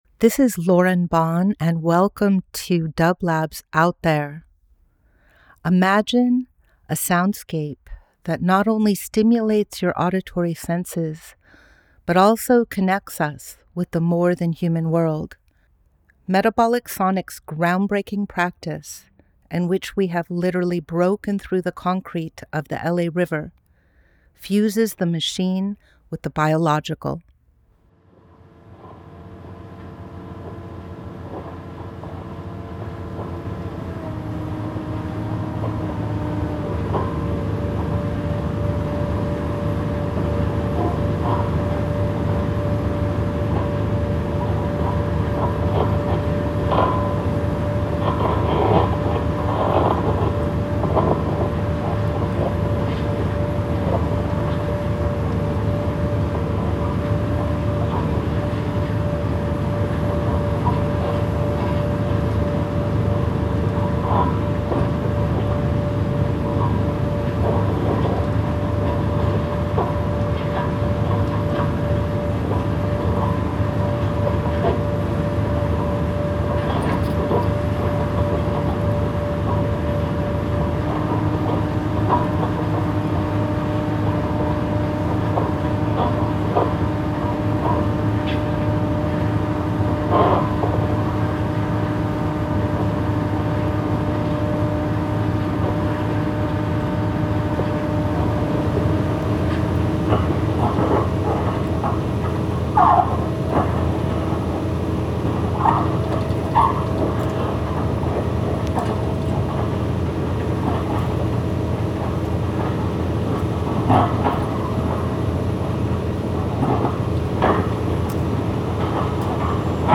Each week we present a long-form field recording that will transport you through the power of sound.
This week we are sharing the sounds of drilling with a 75’ drill to make the opening for 60’ plates to be vibrated deep into the ground to shore up the earth for the mother well at Metabolic Studio. You’ll hear machinery and its reverberation in water tanks and the infrastructure of the buildings.